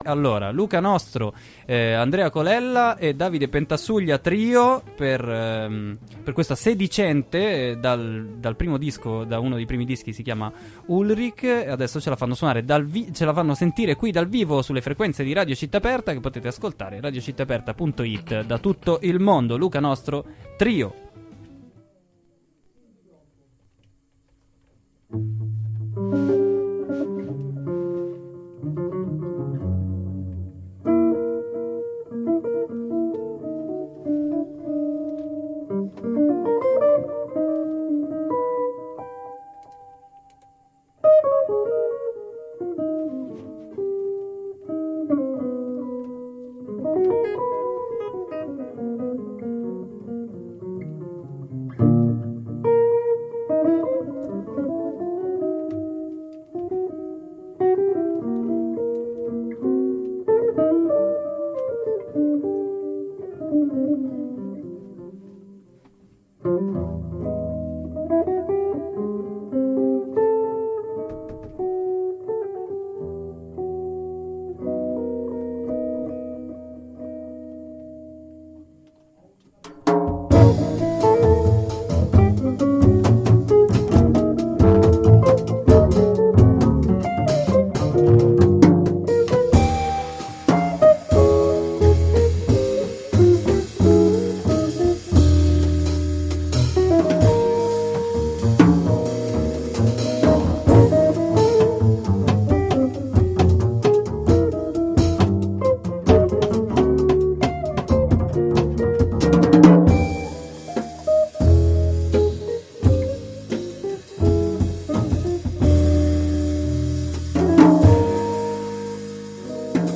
suonano dal vivo dalla Saletta Acquario